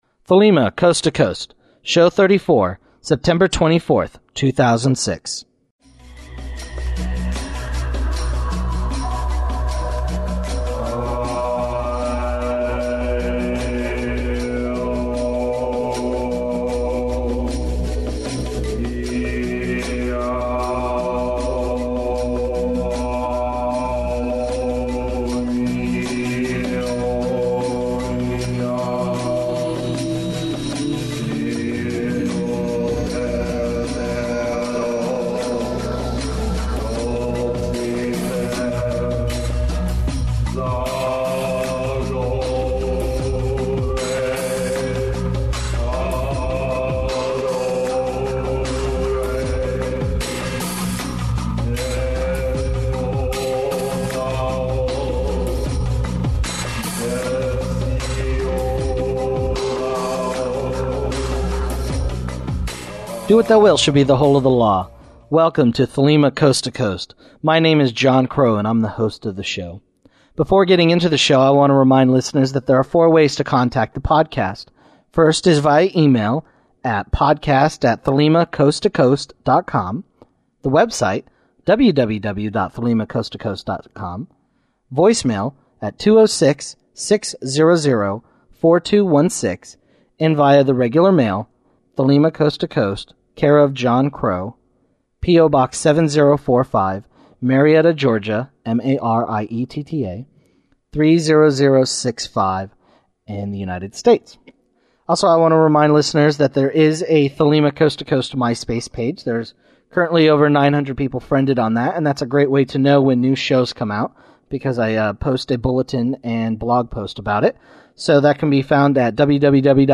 Opening remarks Interview